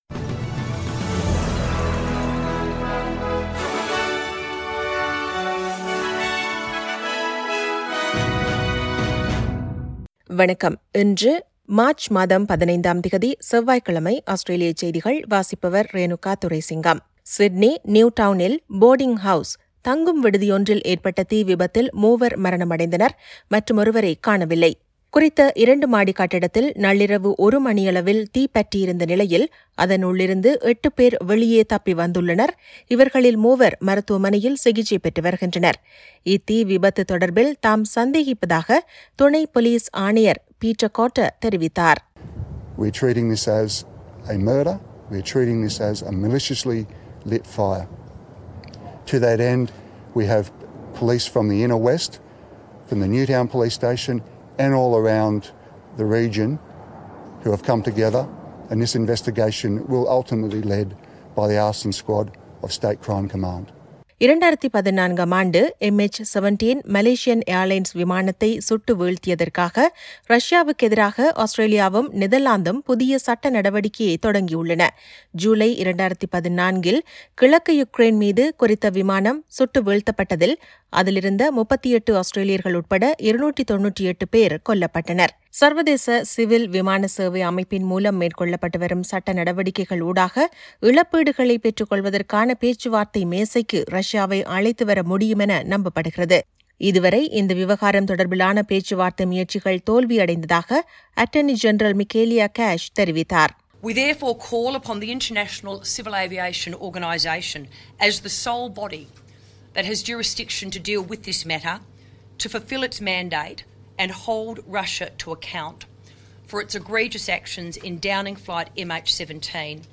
Australian news bulletin for Tuesday 15 March 2022.